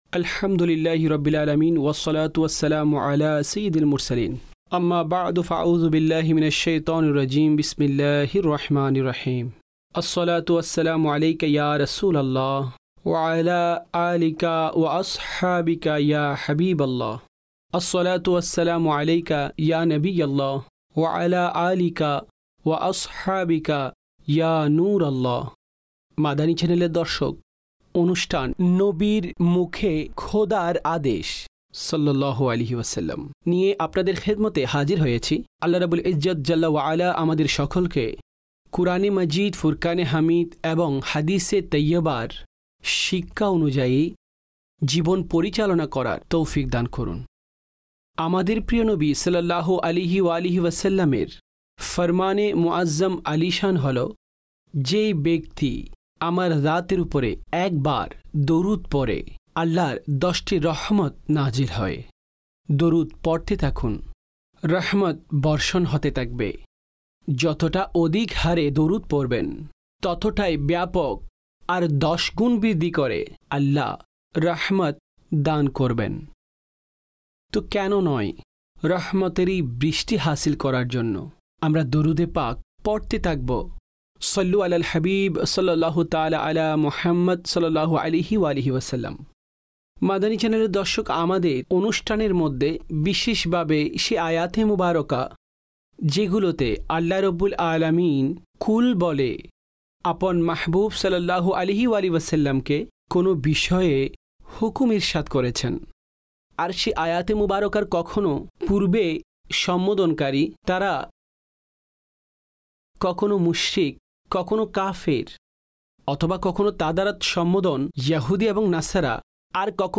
(বাংলায় ডাবিংকৃত)